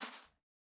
Bare Step Grass Medium D.wav